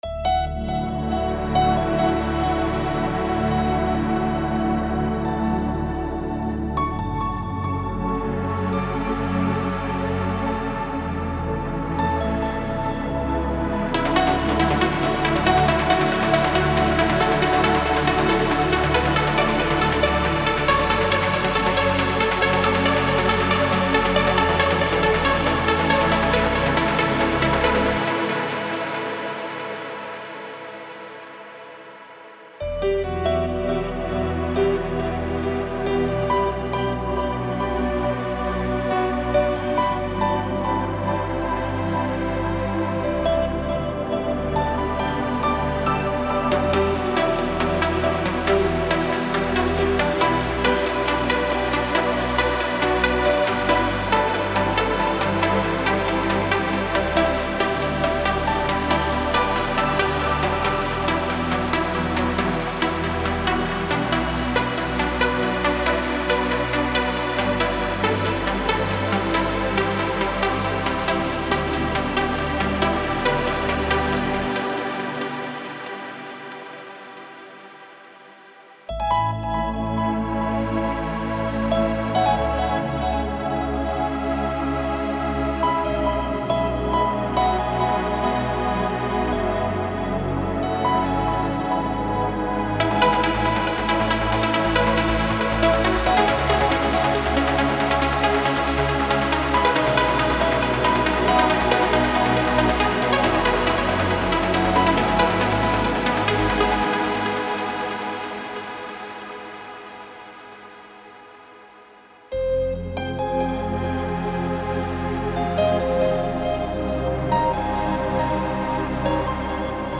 Type: Midi Samples
Piano, Lead, Pad for any Kit Plus Demo